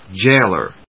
jáil・er, jáil・or /‐lɚ‐lə/